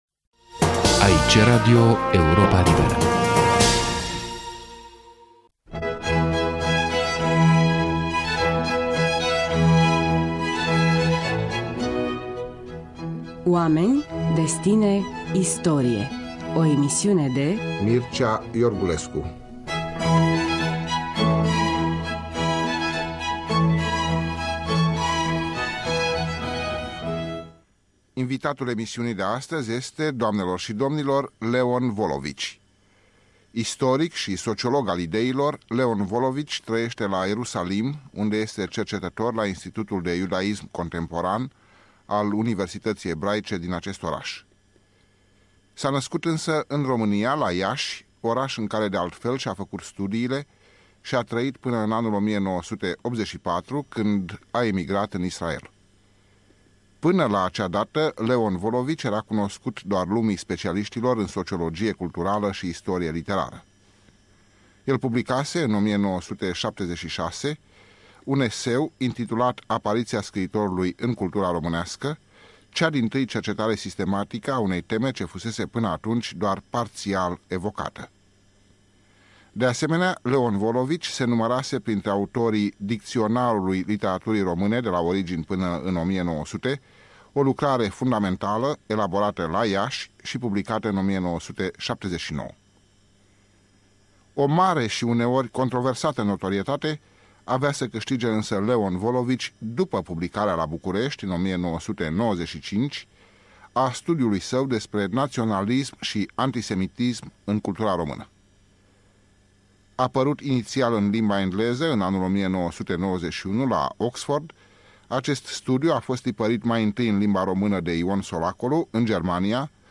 Mircea Iorgulescu în dialog cu Leon Volovici